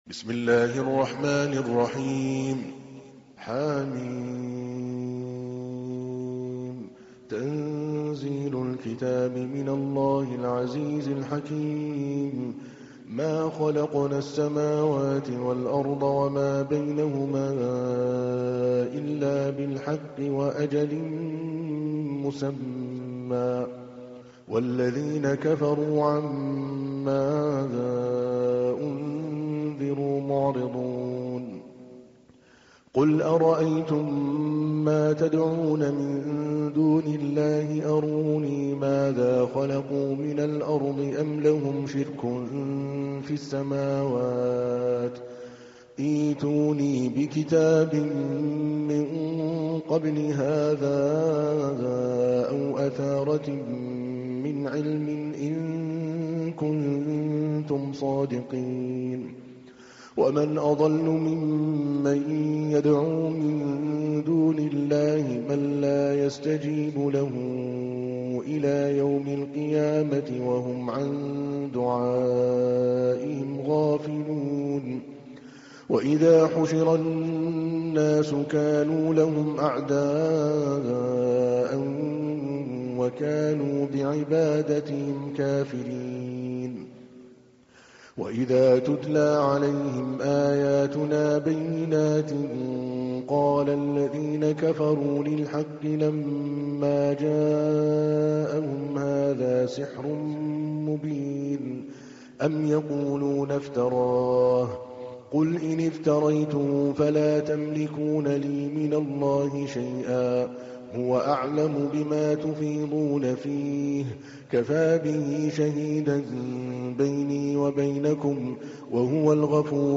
تحميل : 46. سورة الأحقاف / القارئ عادل الكلباني / القرآن الكريم / موقع يا حسين